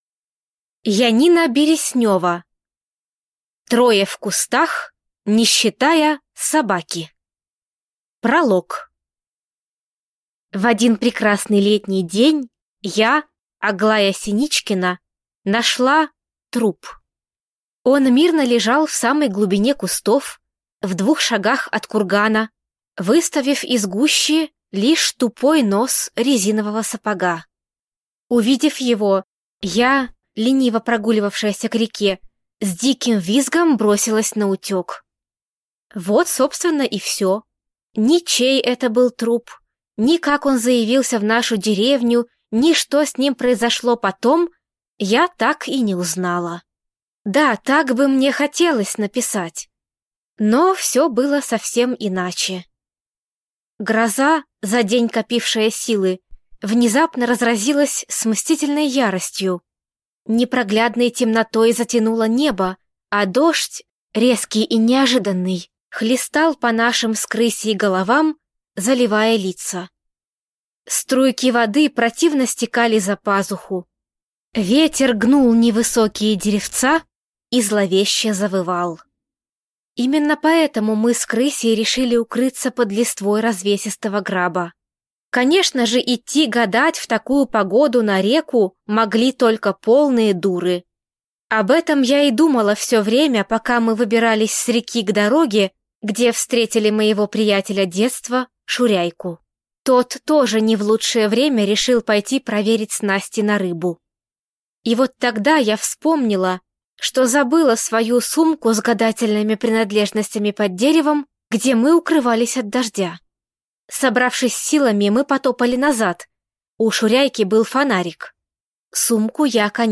Аудиокнига Трое в кустах, не считая собаки | Библиотека аудиокниг